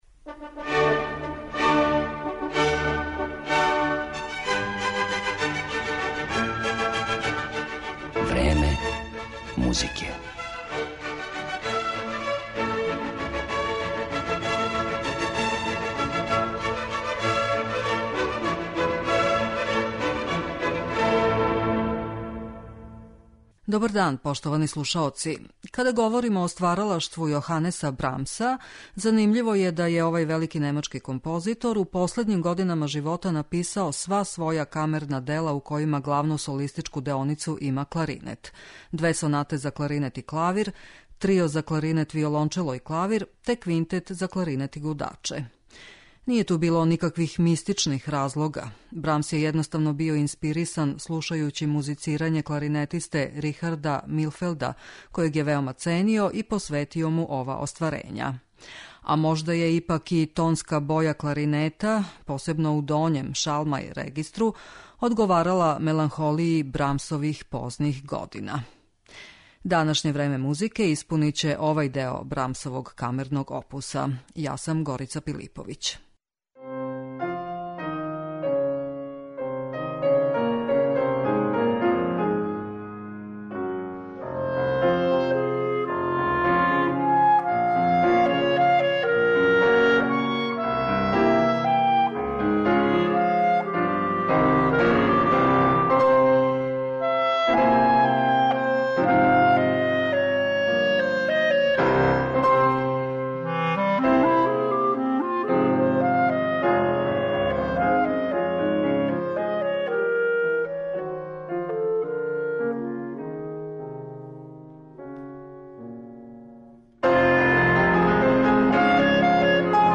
Брамсова камерна дела с кларинетом